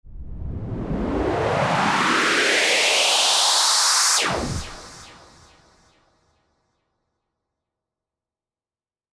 Download Whooshes sound effect for free.
Whooshes